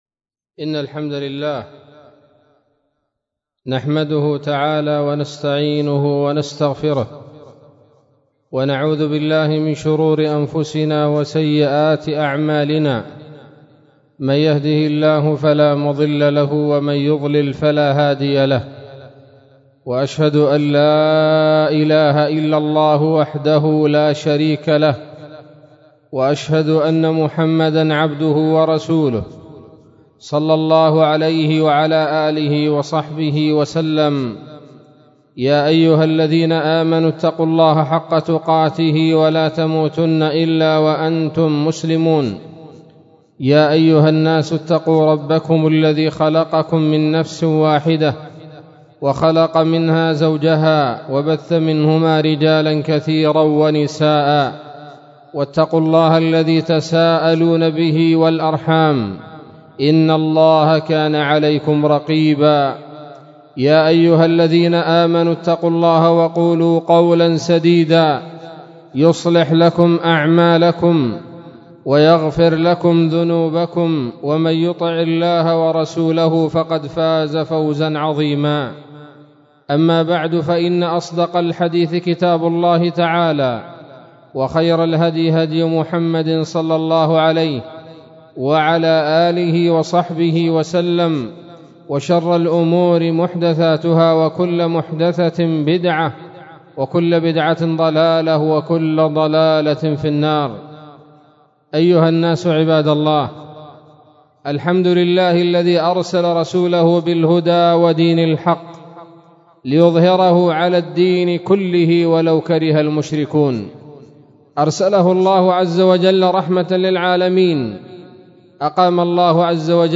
خطبة-جمعة-عن-السنة-الهجرية-الجديدة-6-محرم-1438-هـ.mp3